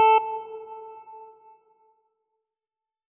A2.wav